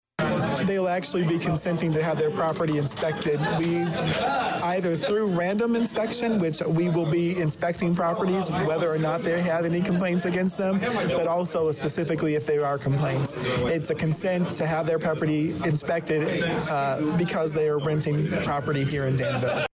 As Mayor Rickey Williams, Jr explained afterwards, by signing the new registration form, landlords will be consenting to both random and follow up inspections to their properties.